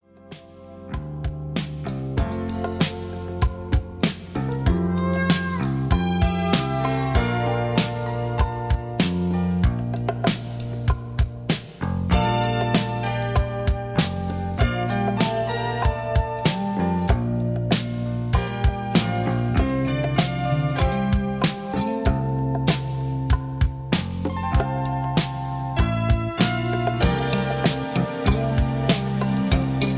(Version-4, Key-C) Karaoke MP3 Backing Tracks
Just Plain & Simply "GREAT MUSIC" (No Lyrics).